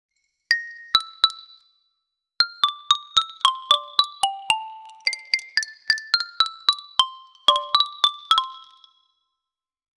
water-xylophone--4zp7y5fm.wav